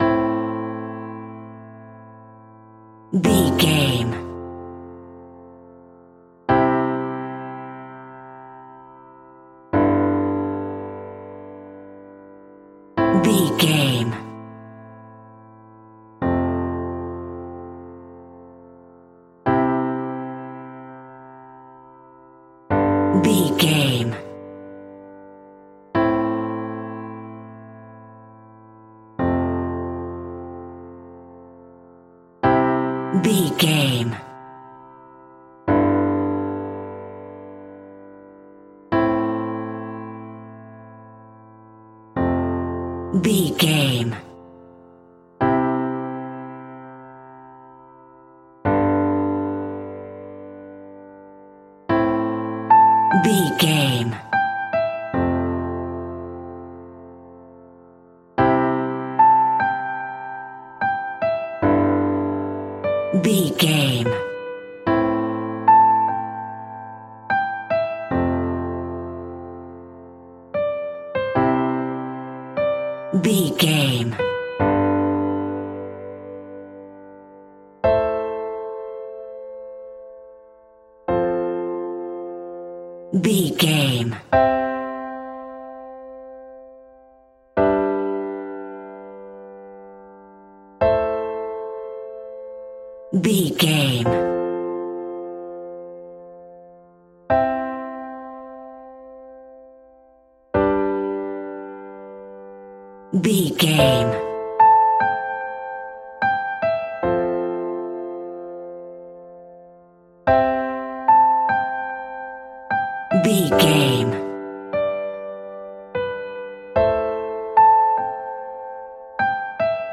Simple and basic piano music in a major key.
Regal and romantic, a classy piece of classical music.
Aeolian/Minor
romantic
soft